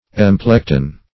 Search Result for " emplecton" : The Collaborative International Dictionary of English v.0.48: Emplecton \Em*plec"ton\, n. [F. or L. emplecton, fr. Gr.